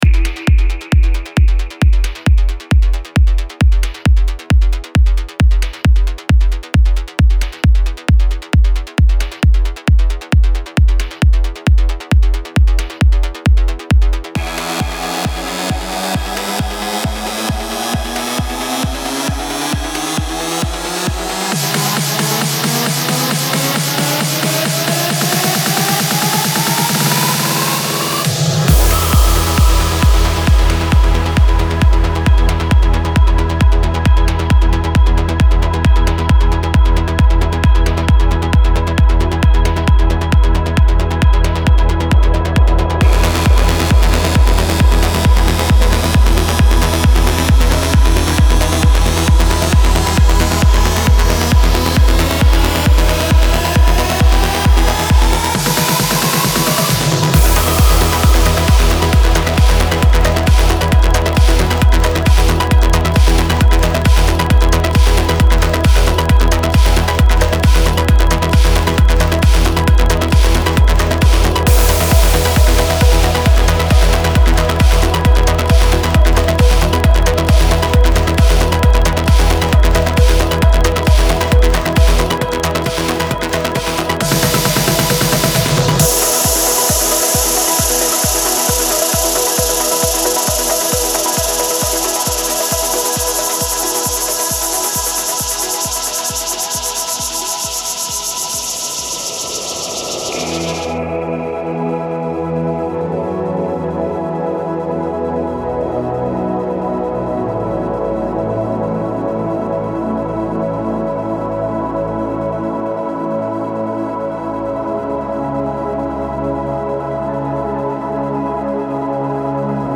Файл в обменнике2 Myзыкa->DJ's, транс
Стиль: Progressive Trance